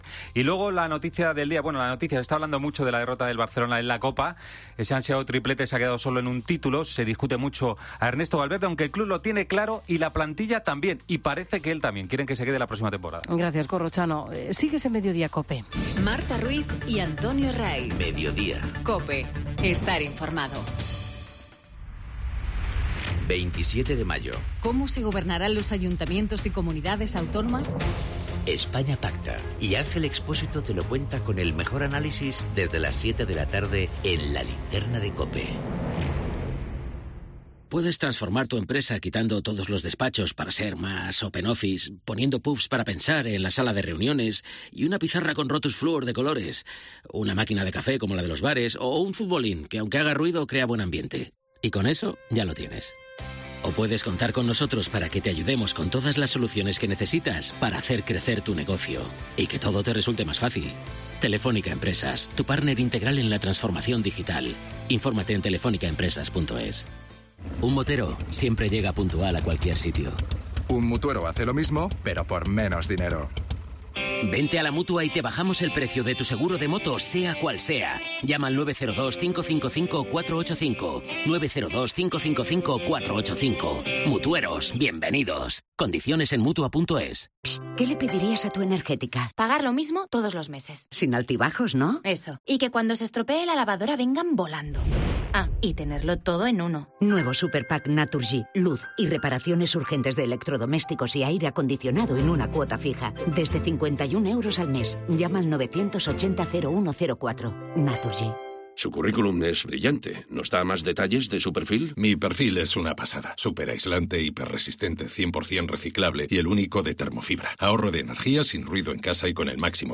Informativo Mediodía 27 mayo 14:20h